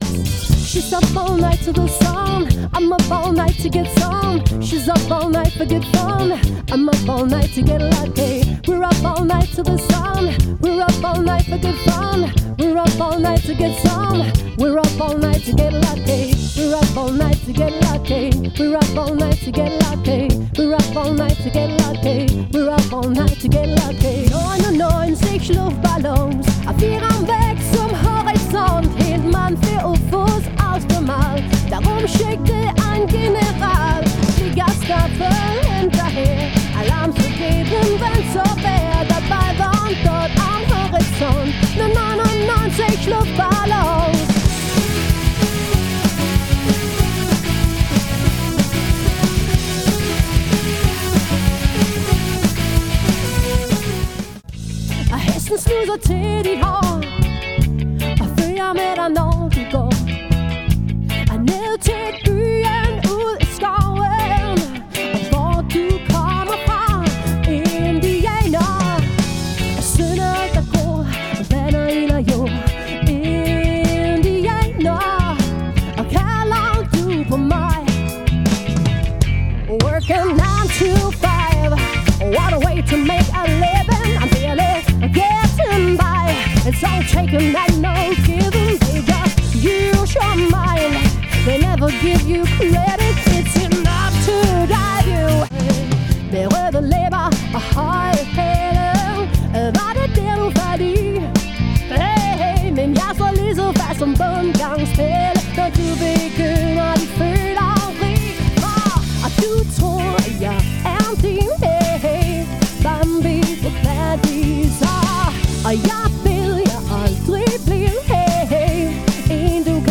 Vi er 5 musikere med en kvindelig vokal i front.
• Allround Partyband
• Coverband
• Rockband